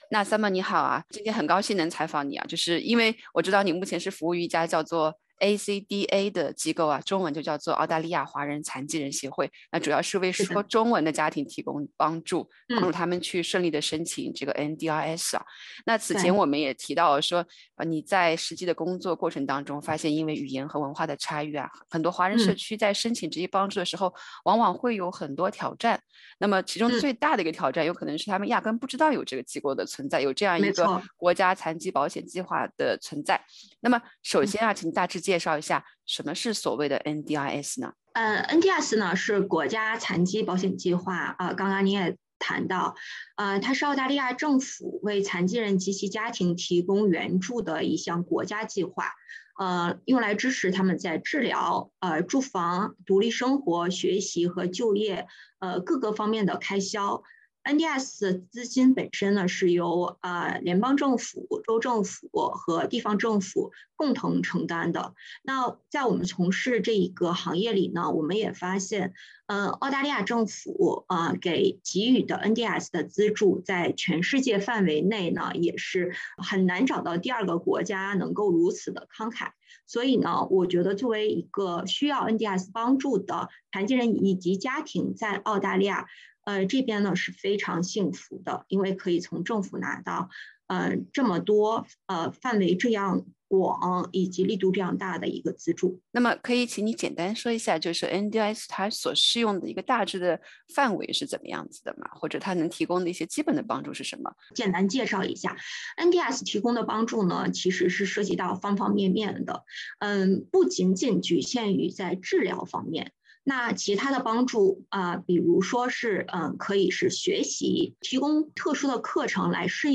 对于很多家有自闭症儿童患者的家庭来说，并非所有人都了解可以寻求NDIS的早期干预，据非盈利机构ACDA观察，这种因不了解NDIS的支持而错过早期干预最佳时期的情况在澳大利亚华人家庭中并不少见（点击上方音频，收听完整采访）。